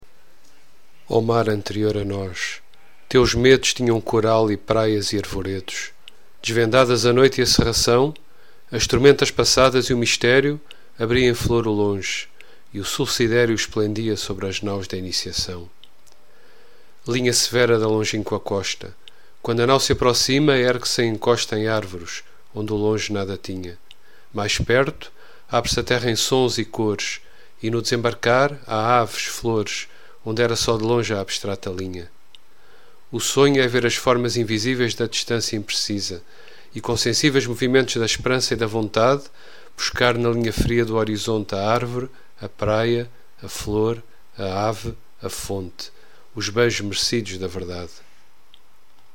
LEARN PORTUGUESE FROM POETRY- read about